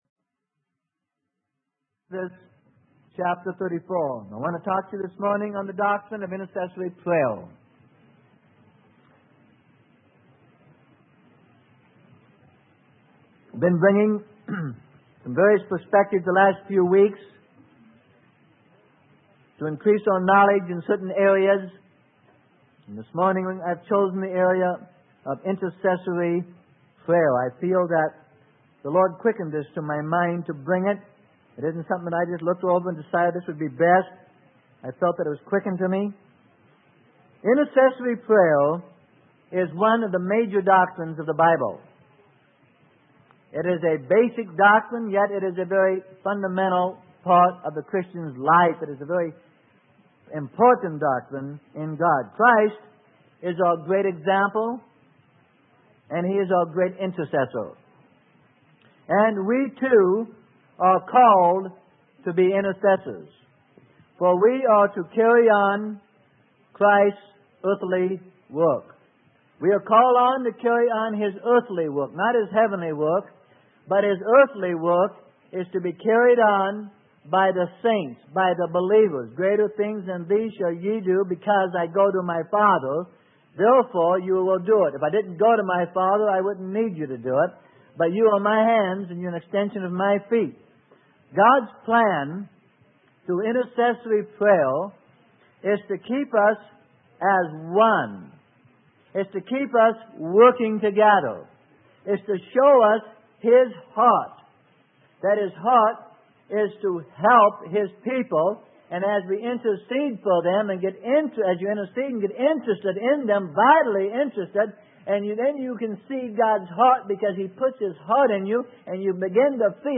Sermon: Intercessory Prayer - Freely Given Online Library